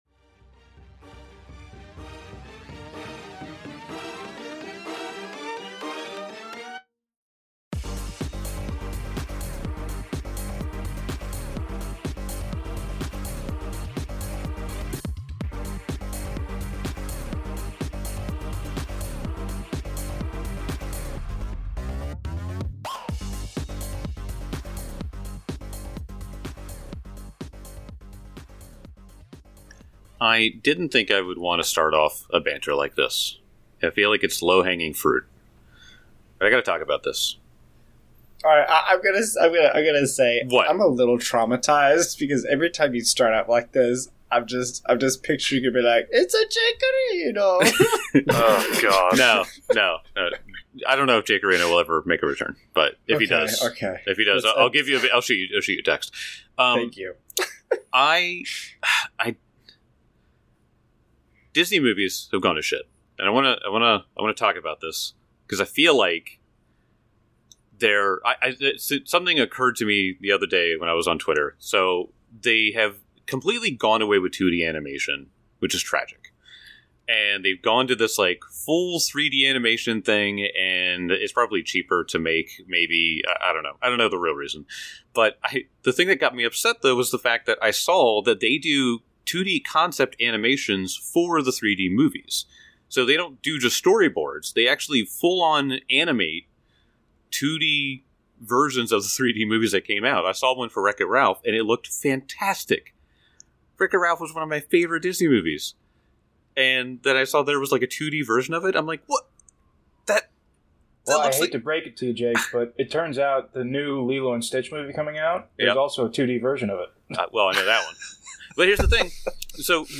Actual play podcast of the Pathfinder 2e, Age of Ashes adventure path produced by Paizo. Five nerdy best friends who love to play pretend with dice!